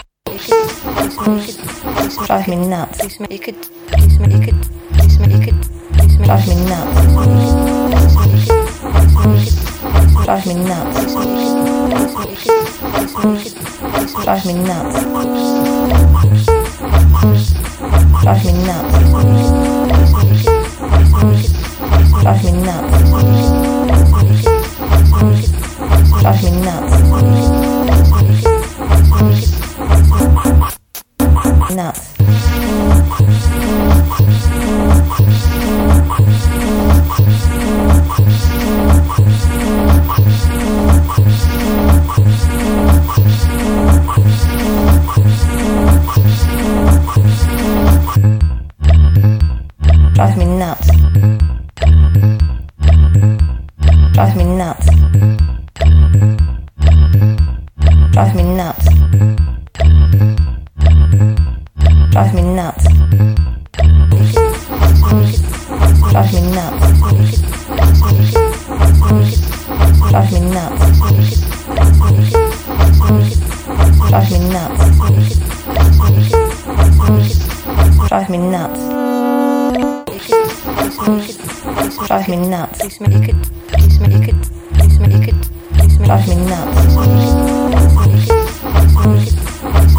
Electro Techno